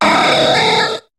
Cri de Deoxys dans Pokémon HOME.